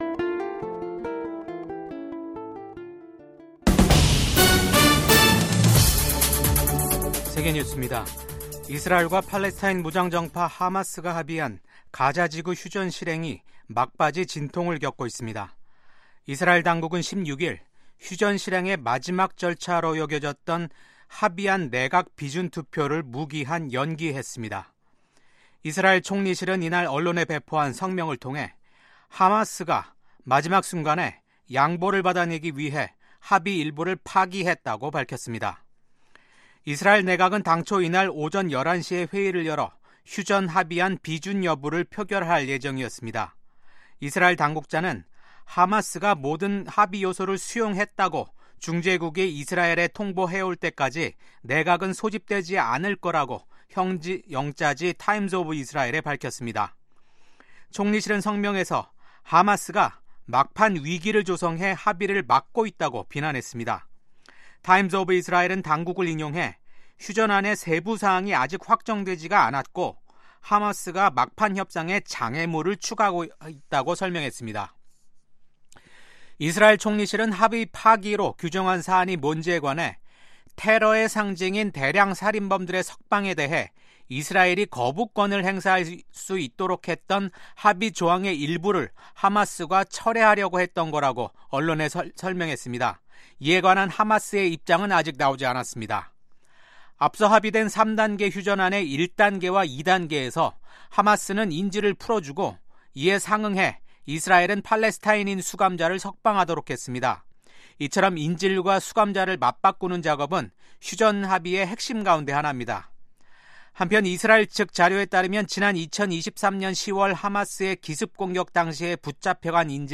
VOA 한국어 아침 뉴스 프로그램 '워싱턴 뉴스 광장'입니다. 조 바이든 미 행정부와 윤석열 한국 정부가 핵협의그룹(NCG) 출범 등으로 강화시킨 미한 확장억제가 도널드 트럼프 새 행정부 아래에서도 그 기조가 유지될 지 주목됩니다. 미국 국무부는 한국이 정치적 혼란 속에서 민주주의의 공고함과 회복력을 보여주고 있다고 말했습니다.